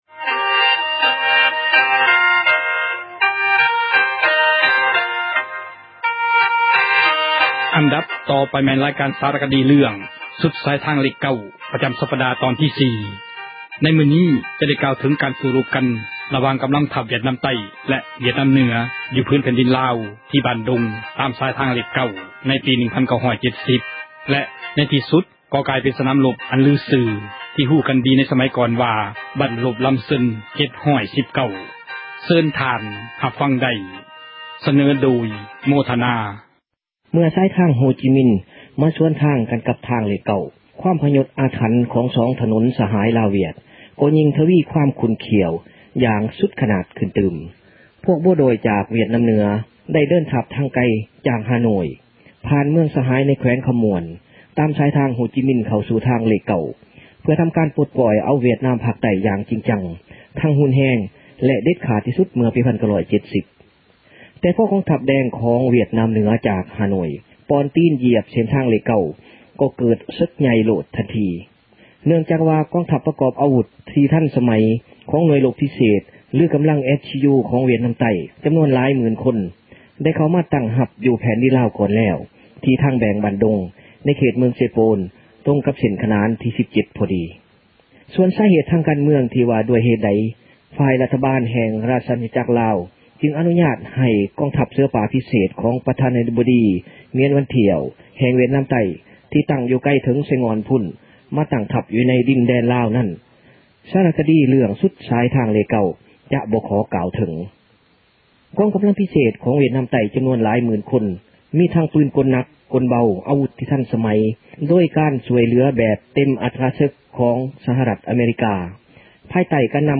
ຣາຍການສາຣະຄະດີ ເຣື້ອງ ”ສຸດສາຍທາງເລຂ 9” ປະຈຳສັປດາ ຕອນທີ 4 ໃນມື້ນີ້ຈະໄດ້ ກ່າວເຖິງການສູ້ຣົບ ກັນຣະຫວ່າງ ກຳລັງທັບວຽດນາມໃຕ້ ແລະວຽດນາມເໜືອ ຢູ່ຜືນແຜ່ນດີນລາວ ທີ່”ບ້ານດົງ” ຕາມສາຍທາງເລຂ9 ໃນປີ 1970 ແລະໃນທີ່ສຸດ ກໍກາຍເປັນ ສນາມຣົບອັນລືຊື່ ທີ່ຮູ້ກັນດີ ໃນເມື່ອກ່ອນວ່າ “ບັ້ນຮົບລຳເຊິງ 719” ເຊີນທ່ານຮັບຟັງໄດ້.